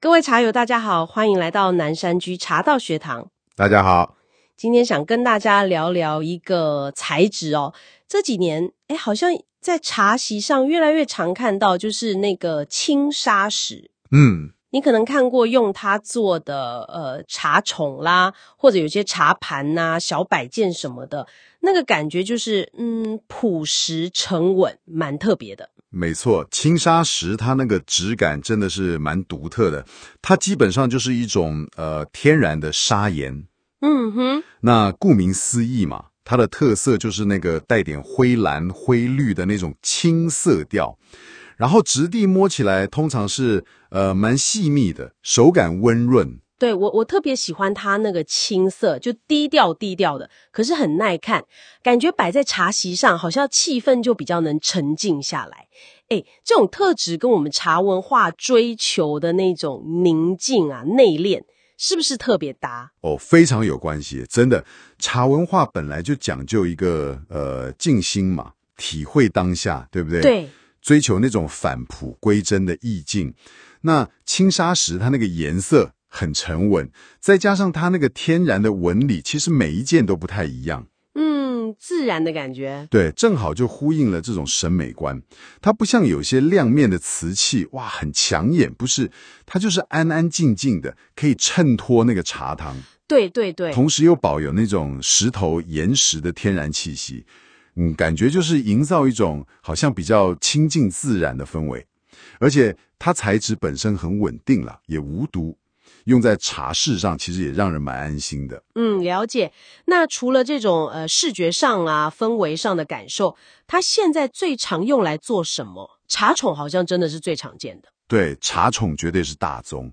【語音導讀】茶文化中的青砂石應用（6分37秒）